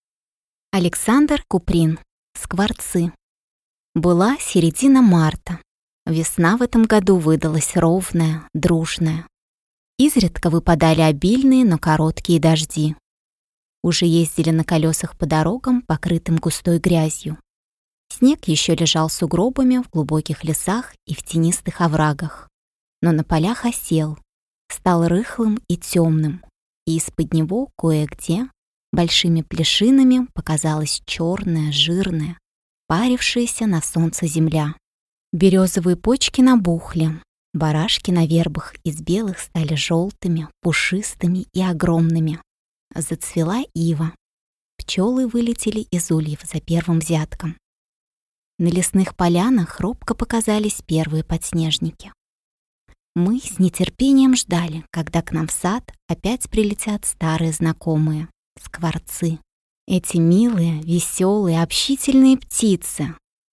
Аудиокнига Скворцы | Библиотека аудиокниг